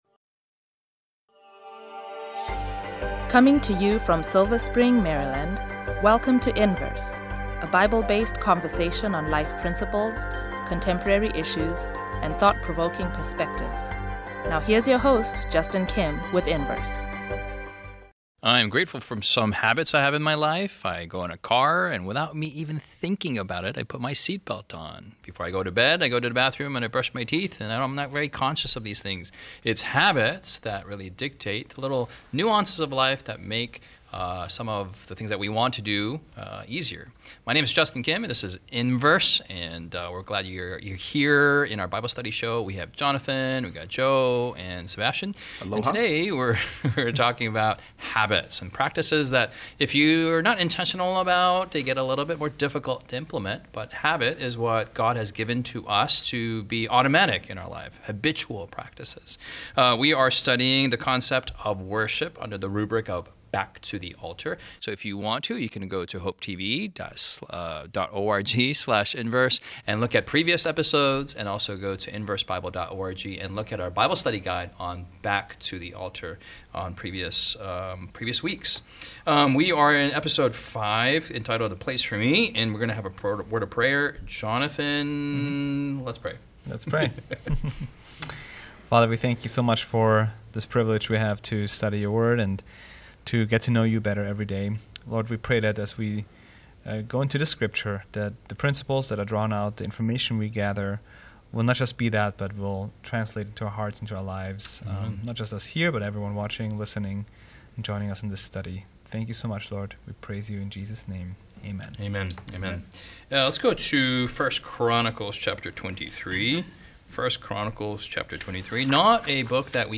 his five friends discuss the latest inVerse Bible study guide topics for young adults.